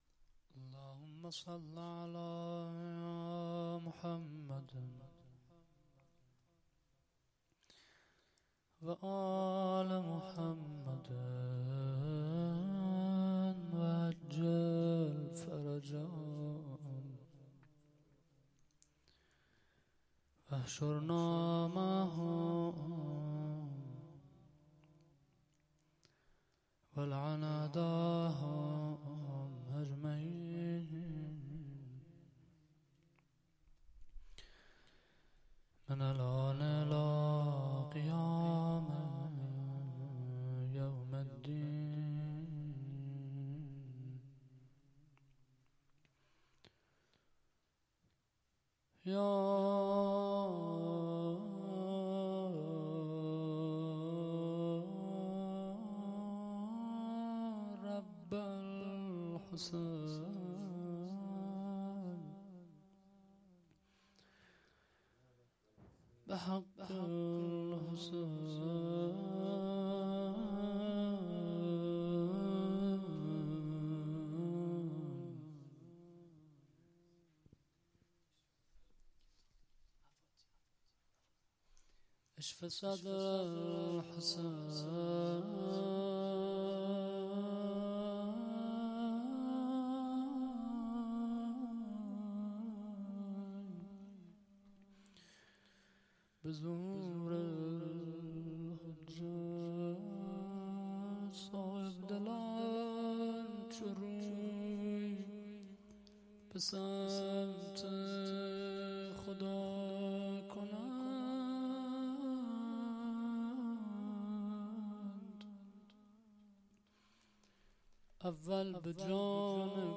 نیایش و روضه